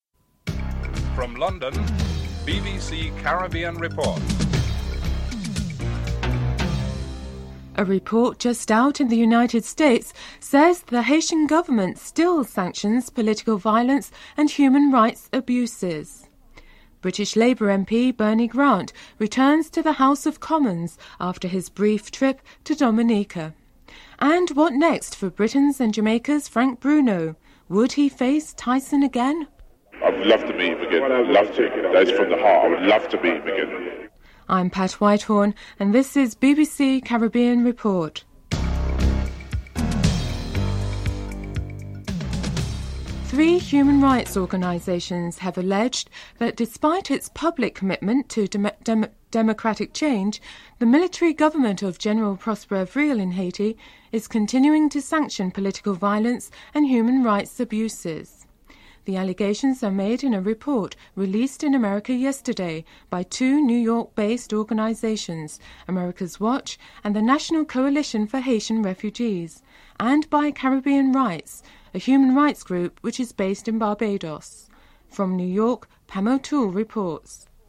Interview with Kenneth Roth, Deputy Director of Human Rights Watch, the parent organisation of Americas Watch.
5. Interview with Bernie Grant, British MP who was keynote speaker at a conference in Dominica and discussed the implications of the creation of a single market in Europe in 1992 (07:16-10:50)